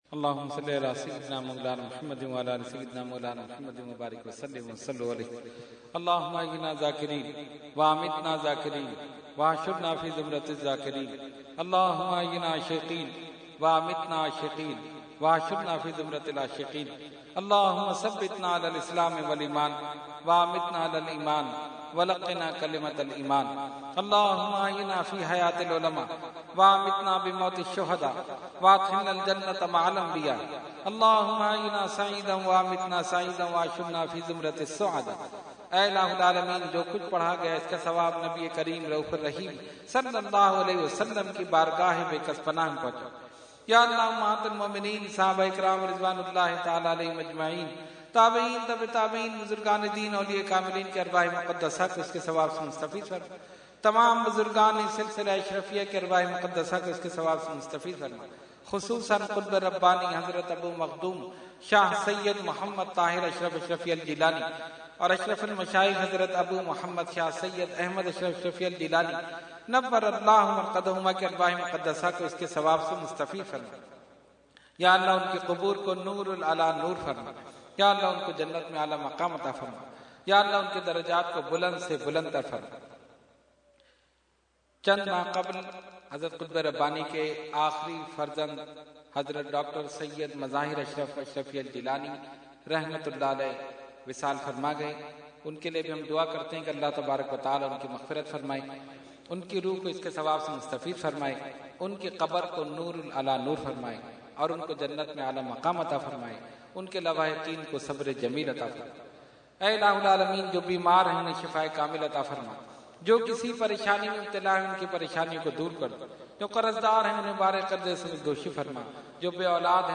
Dua – Urs Qutbe Rabbani 2014 – Dargah Alia Ashrafia Karachi Pakistan